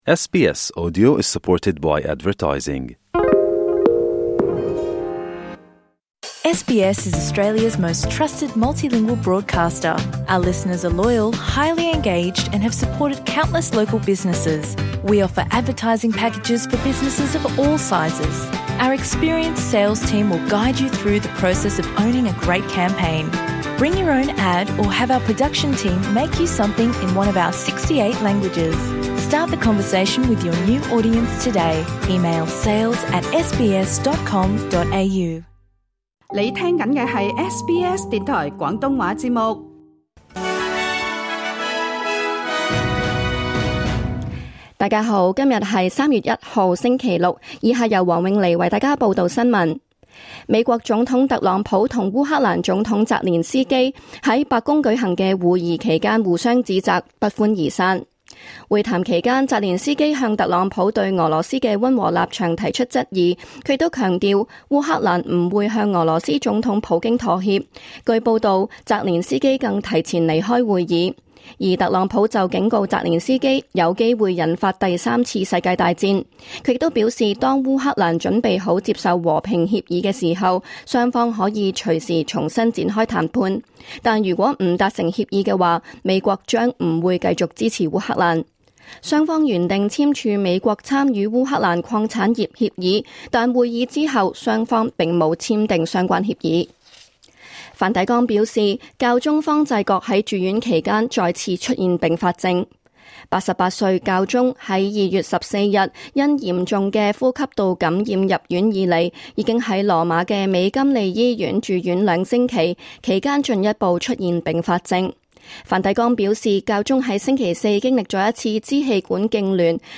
2025 年 3月 1日 SBS 廣東話節目詳盡早晨新聞報道。